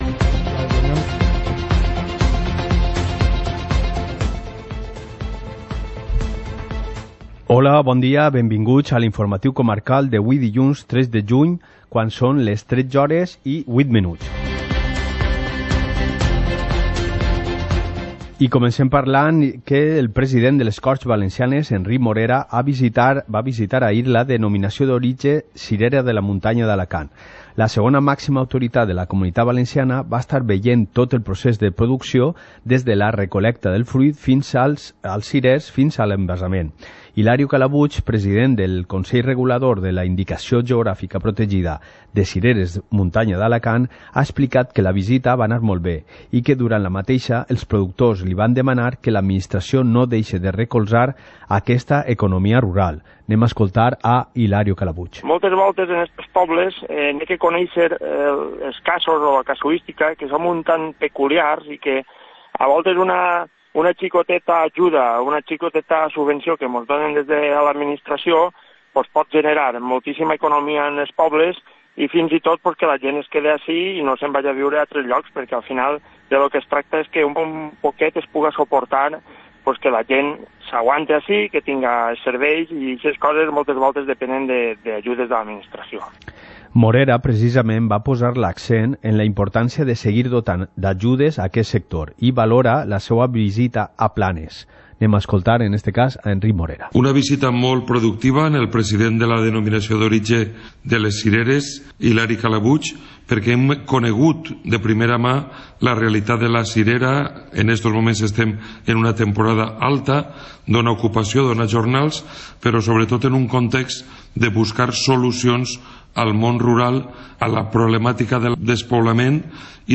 Informativo comarcal - lunes, 03 de junio de 2019